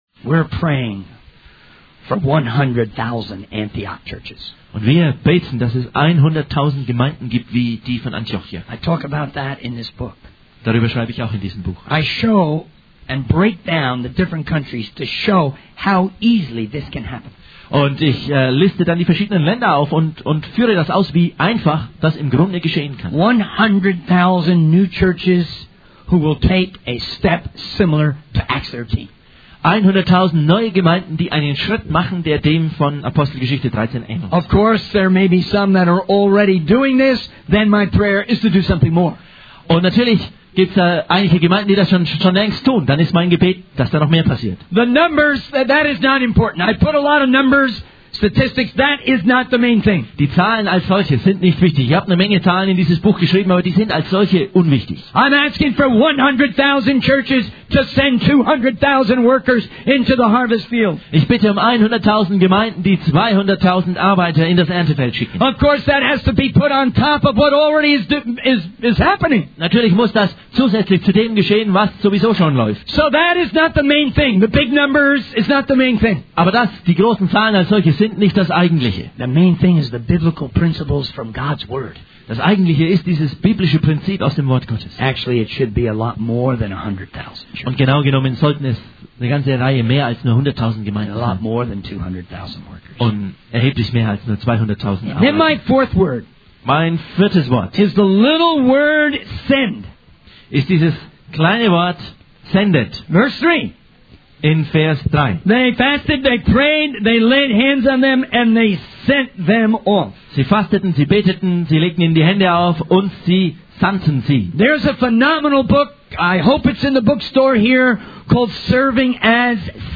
In this sermon, the speaker emphasizes the importance of sending and being sent in the work of the church.